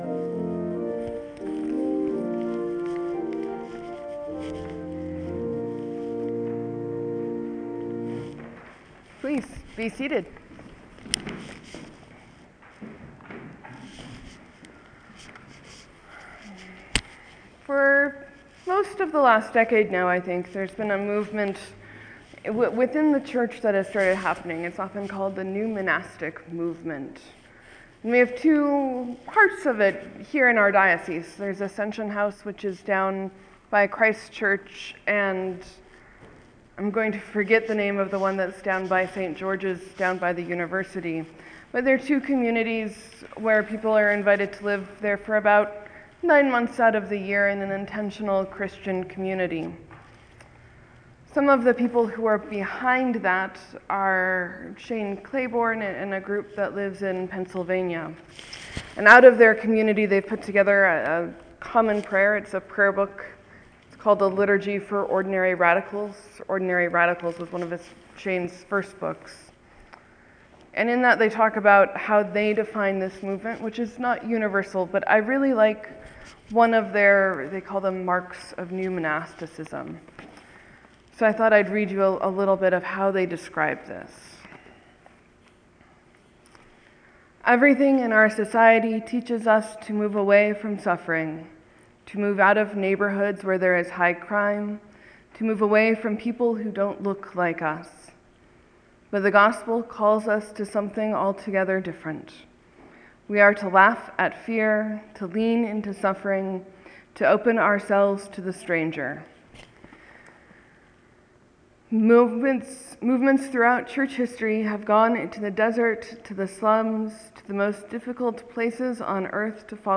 Sermon: Naaman’s wife’s slave-girl does something radical.